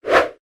Звук смахивания, пролистывания (2)
Библиотека Звуков - Звуки и звуковые эффекты - Приколы, комедия, юмор